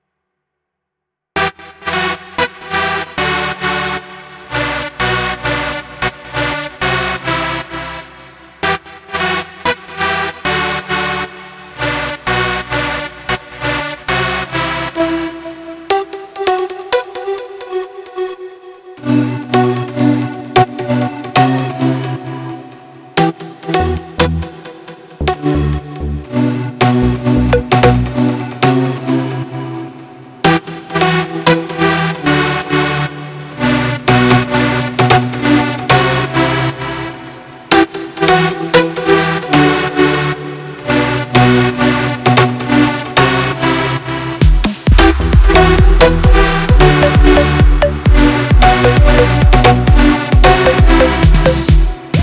Audio_0005.wav איך עושים את כל האפקטים של המשיחה של הצליל באורגן 920 אם אפשר בכלל לעשות באורגן